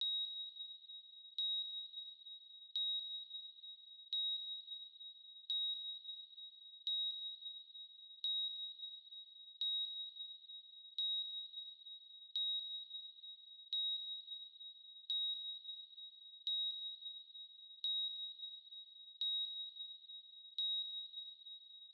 TIMER FX.wav